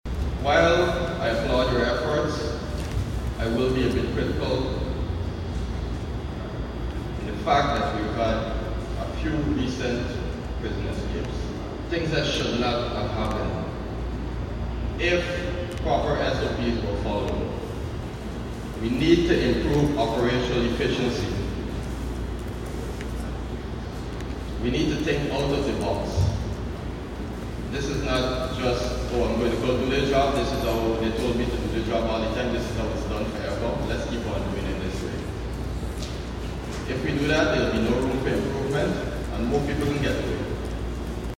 Andre Ally, Permanent Secretary of the Home Affairs Ministry, addressed the Guyana Prison Service’s Annual Conference at the Police Officer Mess, Eve Leary, emphasizing the urgent need to revamp security protocols within the prison system.